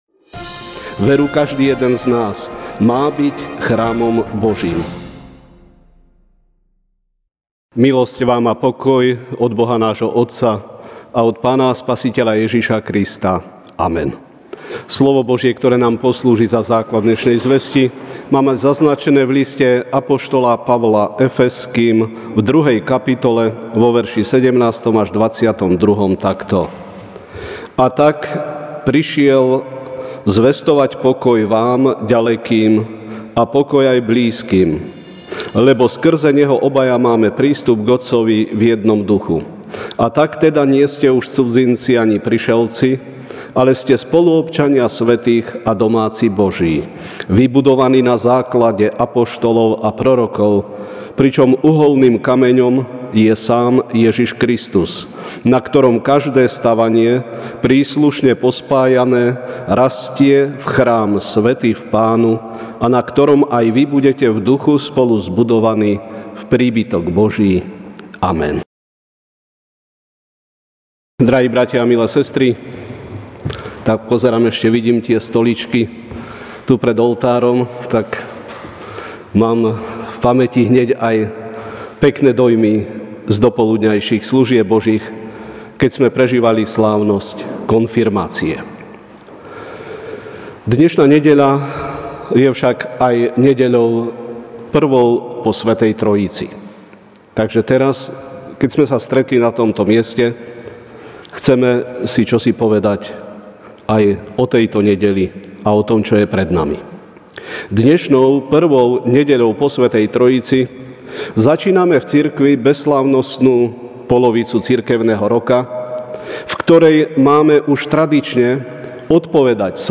Večerná kázeň: Božia stavba (Ef 2, 17-22) A tak prišiel zvestovať pokoj vám ďalekým a pokoj aj blízkym, lebo skrze Neho obaja máme prístup k Otcovi v jednom Duchu.